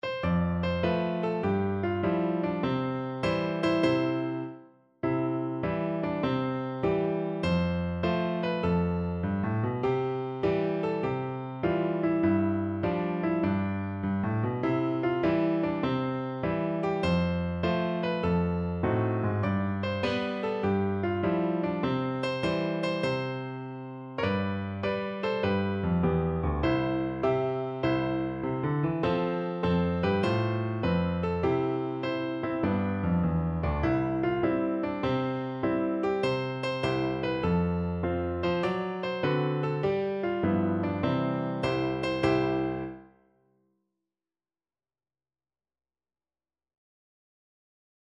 click_go_the_shears_PNO.mp3